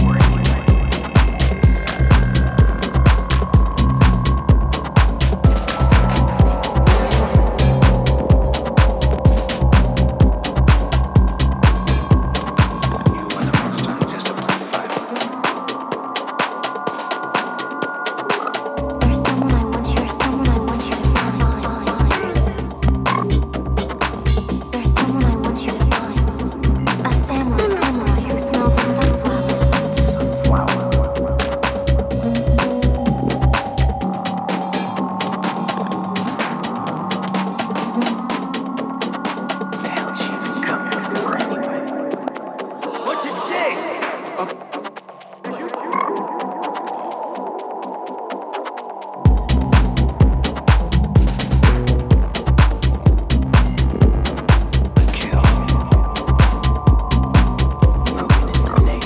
Packed with mesmerising minimal sounds